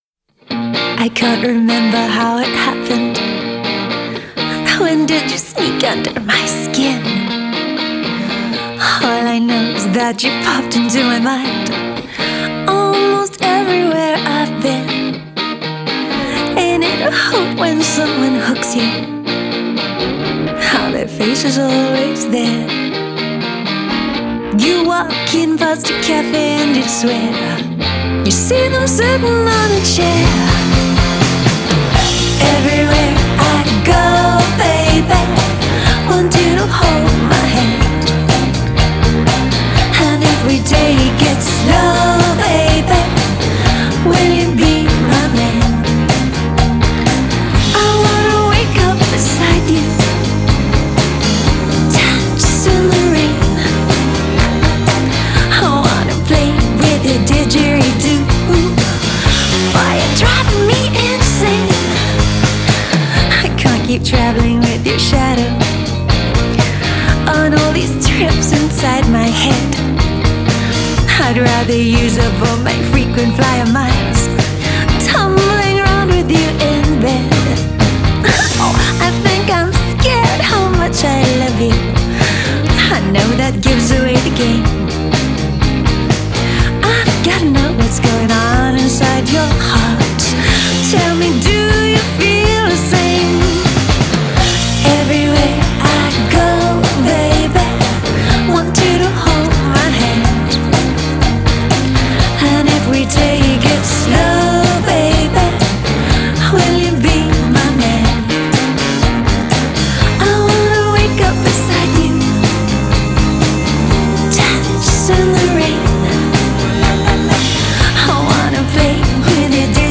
piano and backing vocals
guitar
drums